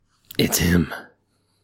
Vocal OneShot: Battalion
描述：One shot recorded through FL Studio and pitched down. Tail is created by convolution reverb.
标签： male battalion human vocal speech one deep shot voice
声道立体声